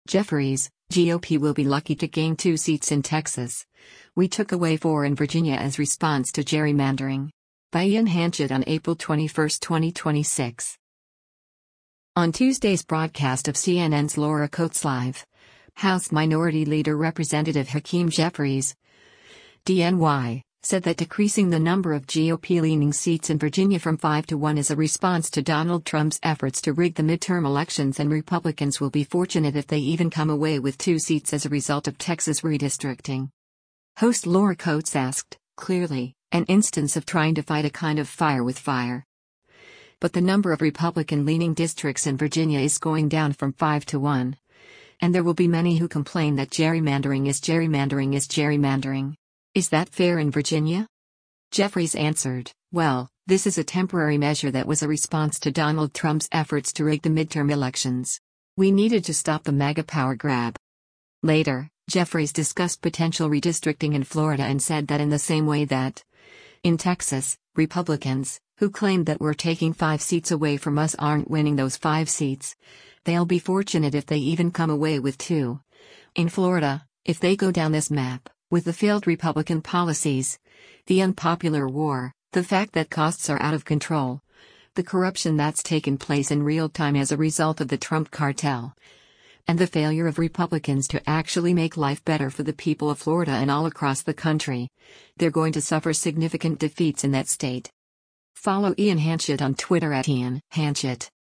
On Tuesday’s broadcast of CNN’s “Laura Coates Live,” House Minority Leader Rep. Hakeem Jeffries (D-NY) said that decreasing the number of GOP-leaning seats in Virginia from five to one is “a response to Donald Trump’s efforts to rig the midterm elections” and Republicans will “be fortunate if they even come away with two” seats as a result of Texas redistricting.